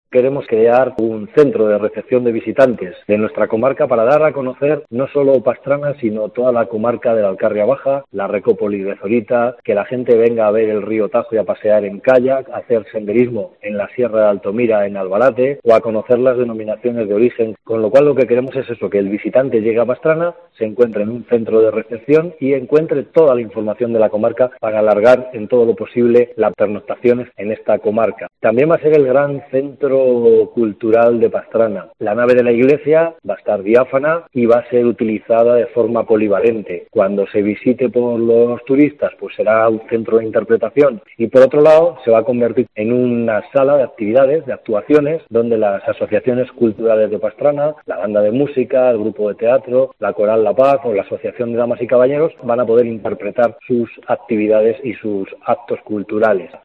El Alcalde pastranero, Ignacio Ranera, señala el objetivo de la restauración.